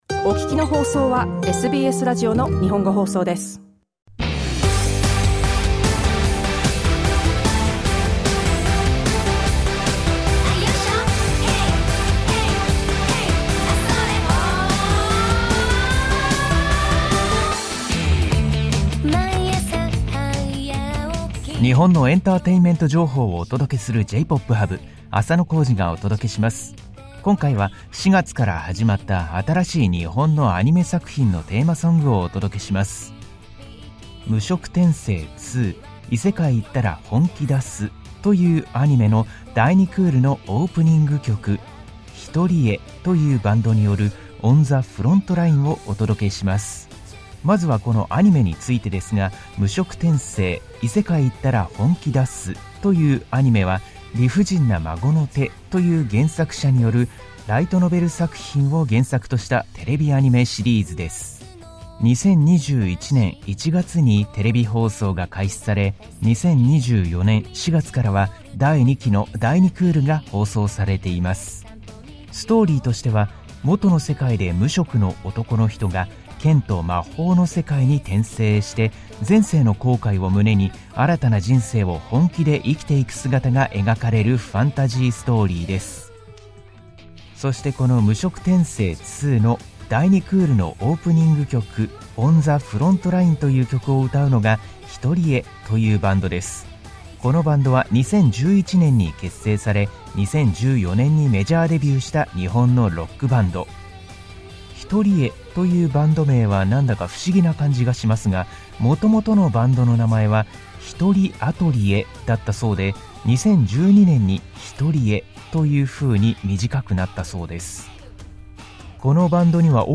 SBS Japanese's music segment J-Pop Hub is broadcast on Thursdays.